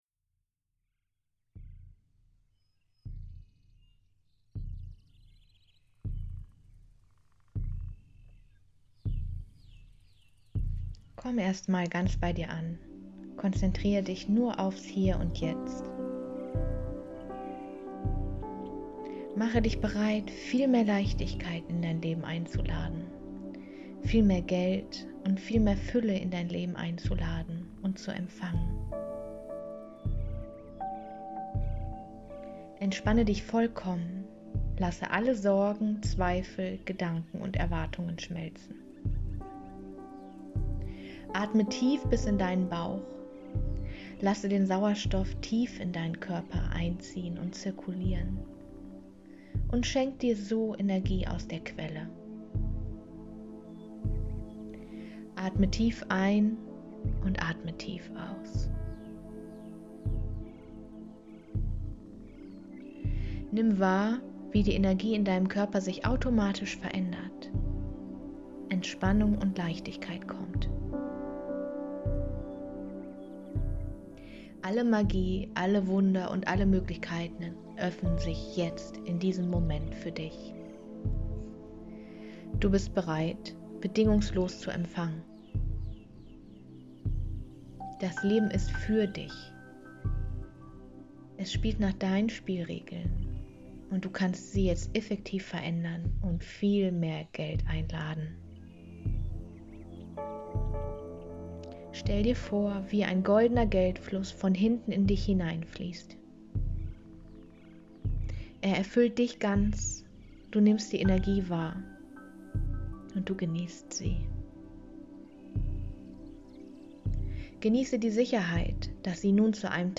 030 Geld-Manifestationsmeditation